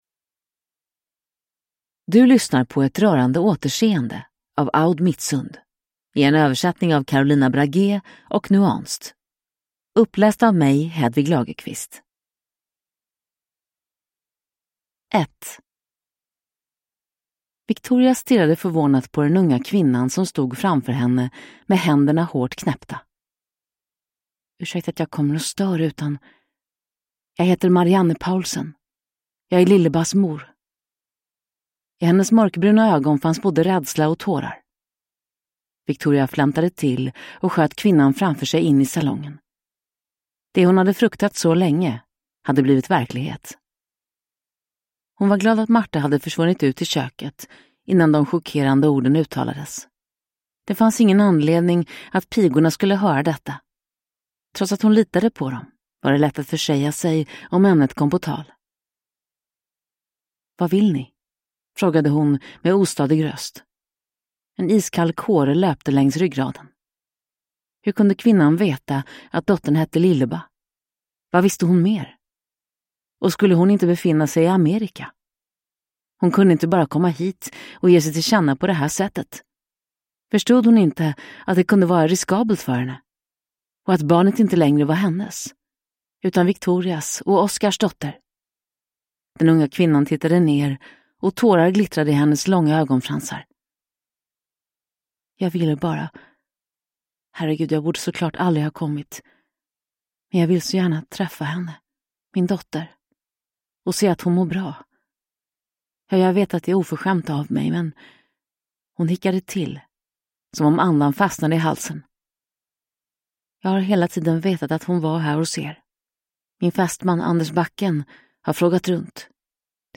Ett rörande återseende – Ljudbok